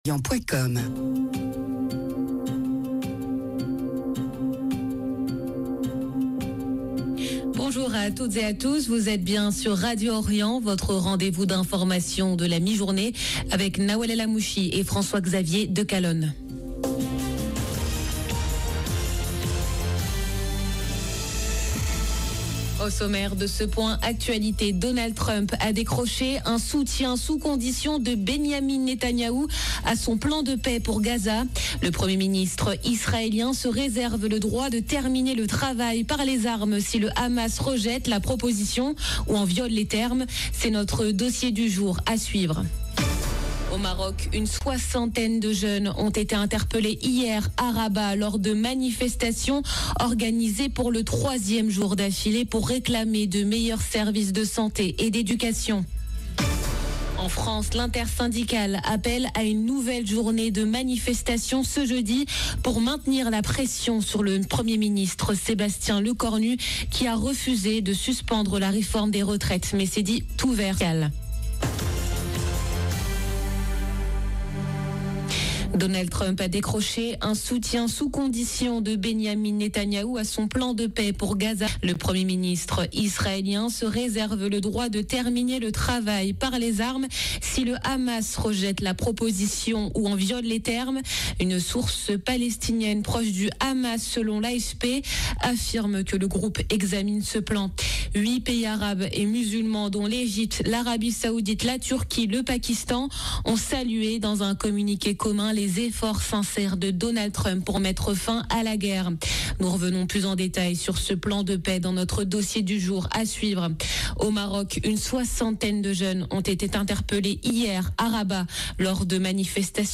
Journal de midi du 30 septembre 2025